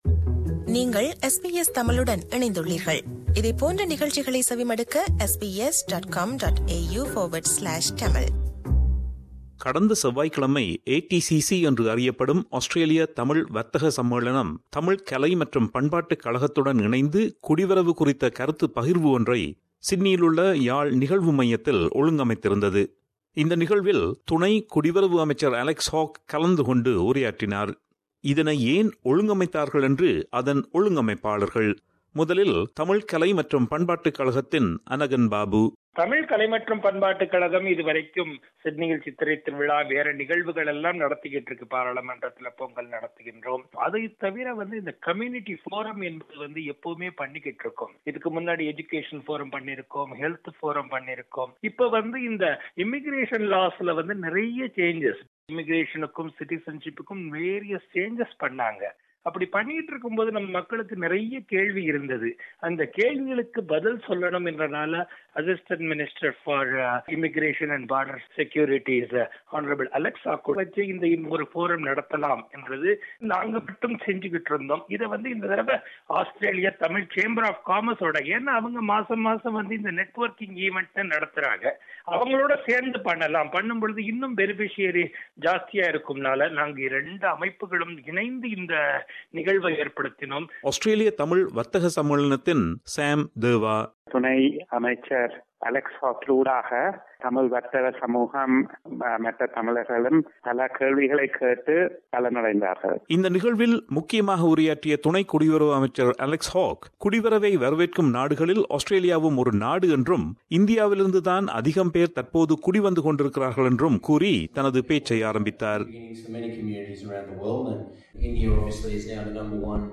Last Tuesday, the Australian Tamil Chamber of Commerce and Tamil Arts and Culture Association, jointly organised a forum to talk about immigration matters. Deputy Minister of Immigration and Border Protection, Mr Alex Hawke MP addressed the gathering.